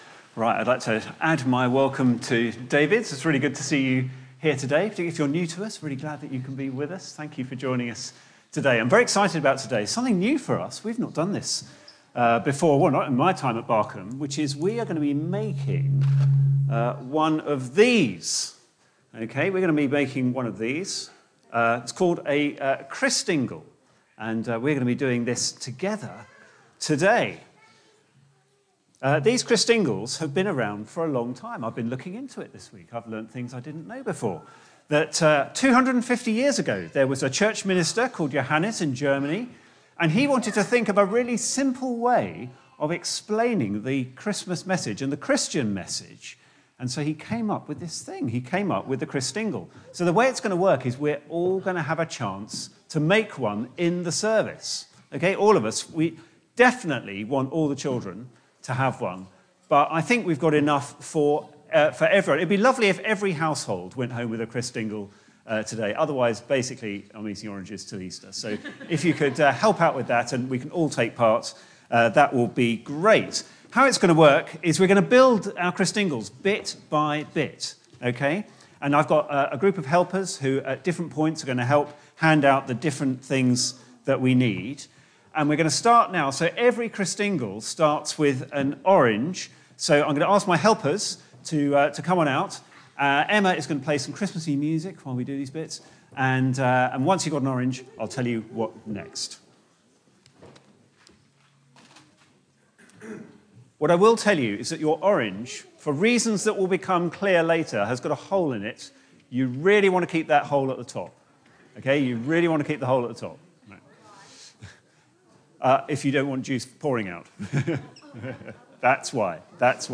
Media for Barkham Morning Service on Sun 03rd Dec 2023 10:00
Theme: Christingle Service Sermon